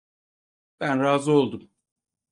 Pronounced as (IPA) /ɾaːˈzɯ/